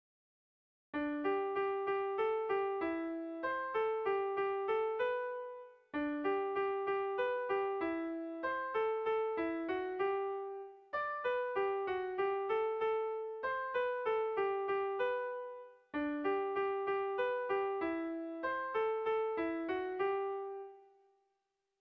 Irrizkoa
Doinu polita.
Zortziko txikia (hg) / Lau puntuko txikia (ip)
A1A2BA2